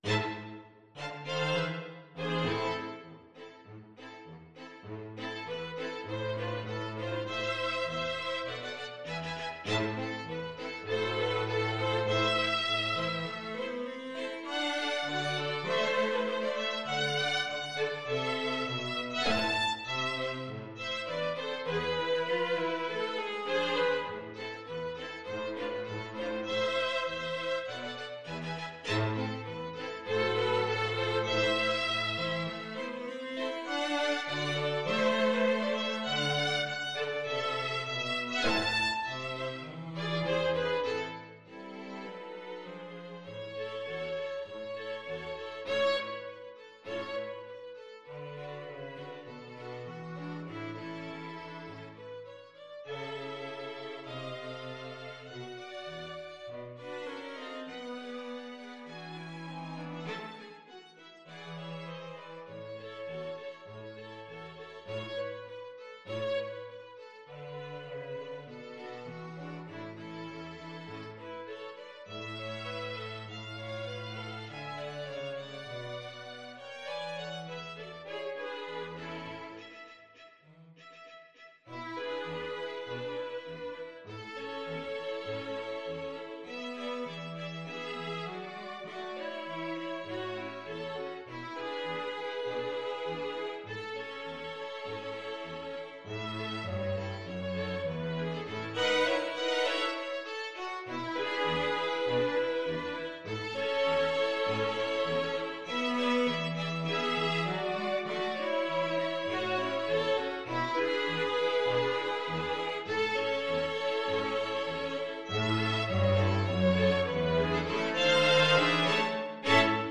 Violin 1Violin 2ViolaCello
Allegro moderato (View more music marked Allegro)
2/4 (View more 2/4 Music)
Classical (View more Classical String Quartet Music)